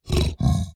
Minecraft Version Minecraft Version 1.21.5 Latest Release | Latest Snapshot 1.21.5 / assets / minecraft / sounds / mob / piglin_brute / idle4.ogg Compare With Compare With Latest Release | Latest Snapshot